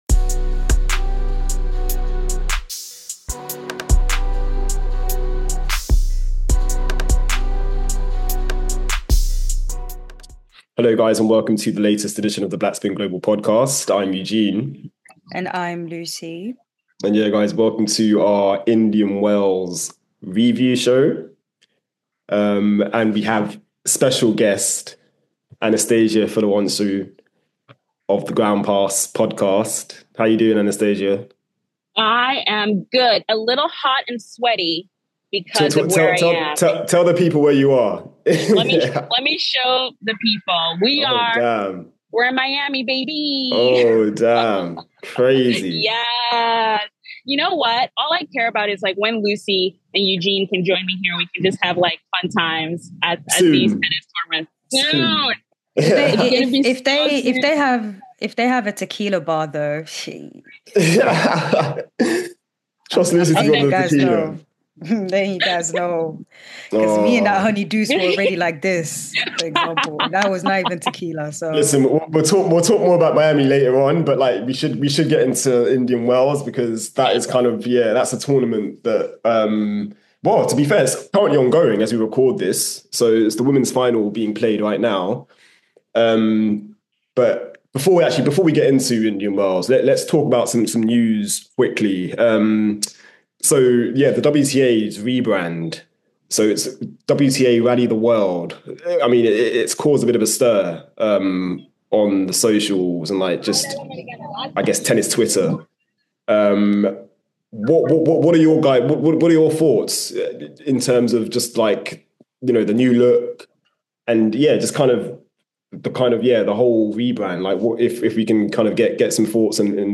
*Disclaimer: this interview was recorded during the 2nd week of Wimbledon 2024.